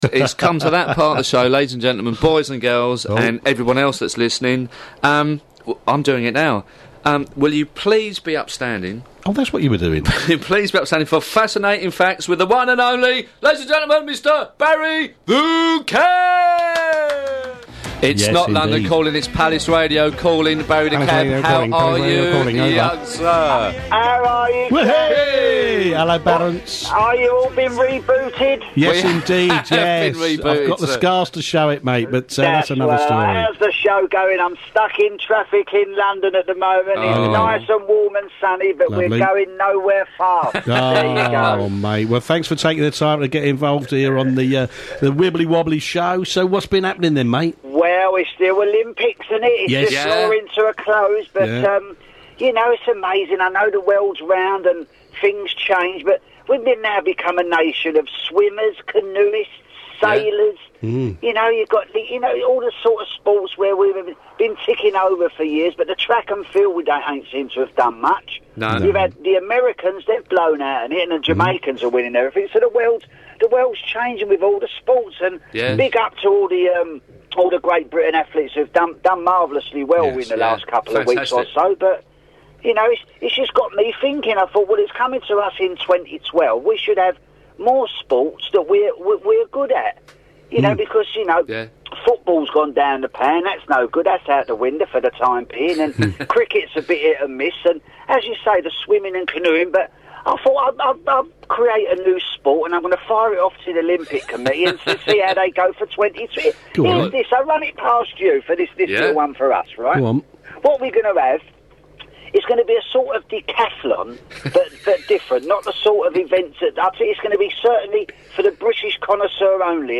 South London cabbie